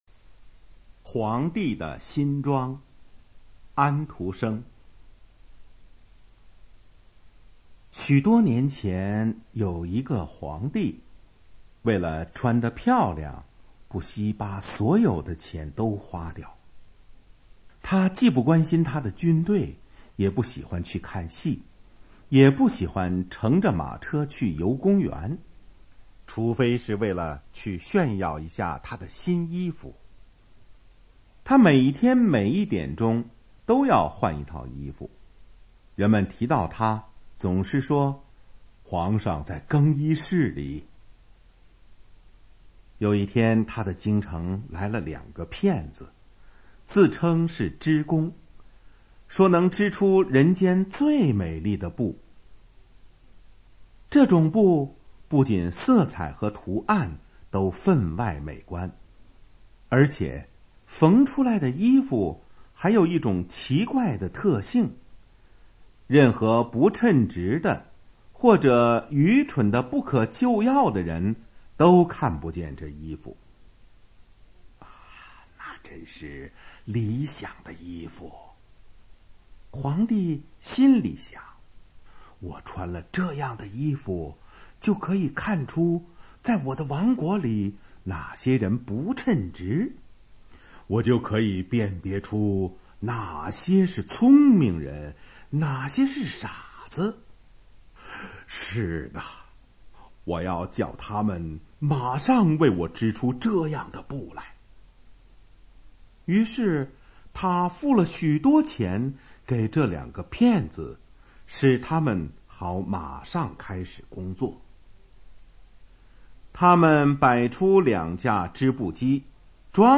《皇帝的新装》MP3朗读 ☆☆☆ 点击下载资料 ☆☆☆